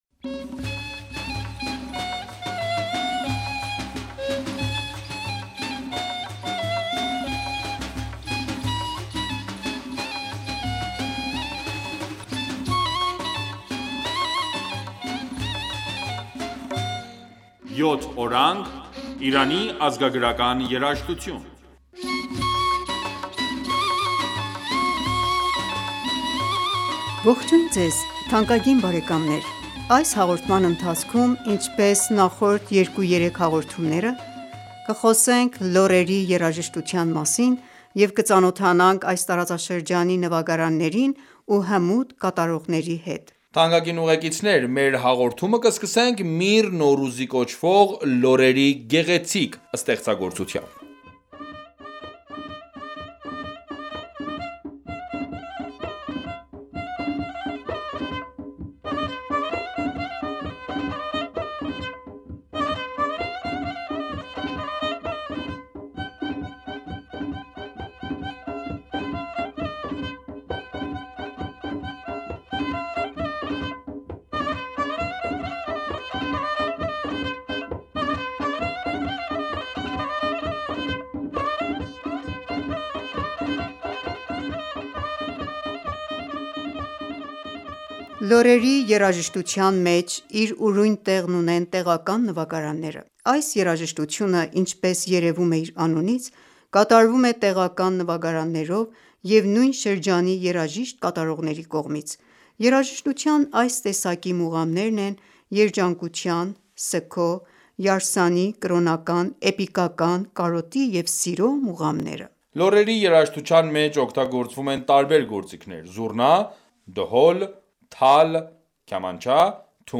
Թանկագին ունկնդիրներ մեր հաղորդումը կսկսենք «Միր նորուզի կոչվող» լոռերի գեղեցիկ ստեղծագործությամբ: